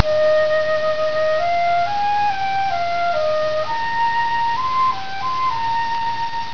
I think I start to notice the distortion (in the form of added static-like noice) about flute5.wav.  But I find it astonishing just how well you can hear the music when only ONE bit is used to represent the amplitude.
flute5.wav